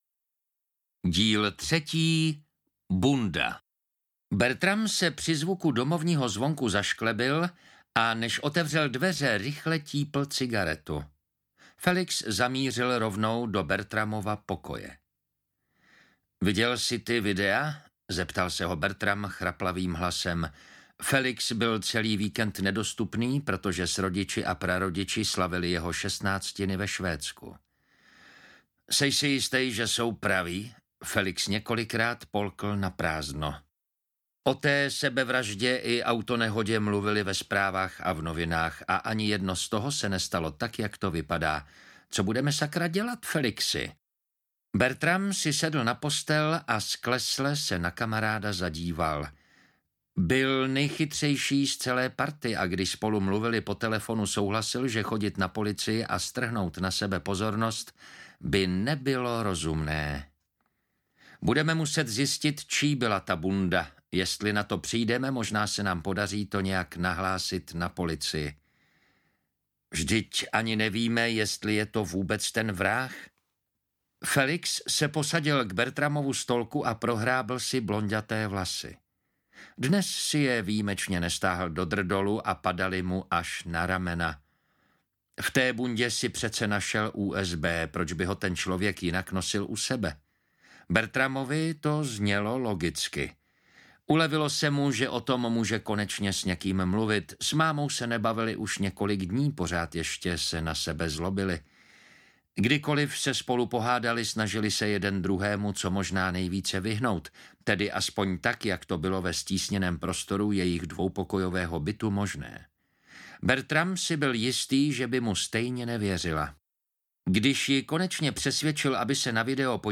Uklízeč 3: Bunda audiokniha
Ukázka z knihy